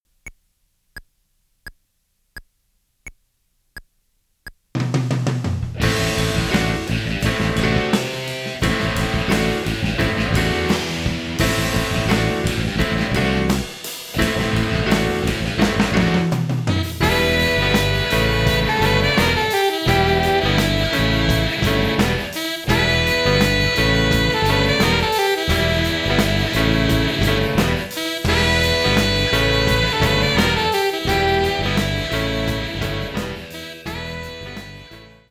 Piano ou Clavier